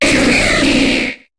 Cri de Roigada dans Pokémon HOME.